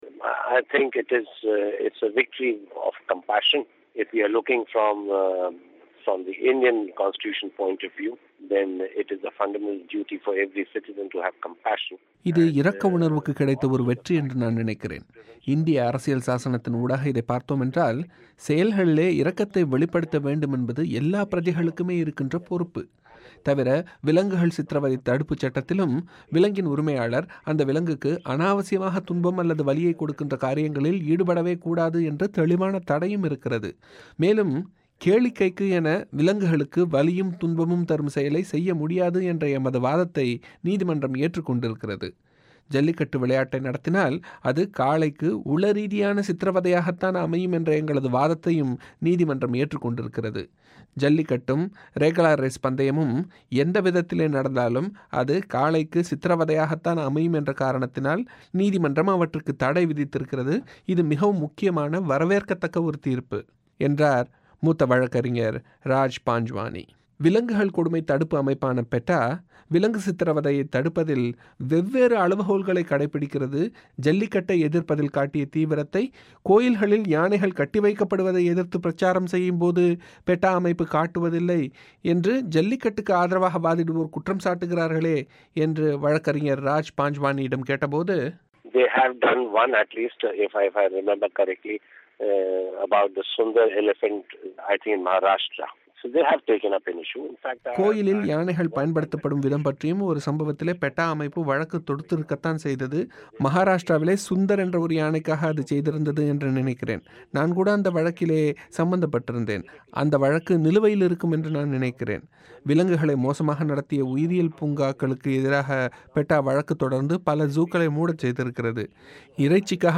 பேட்டி.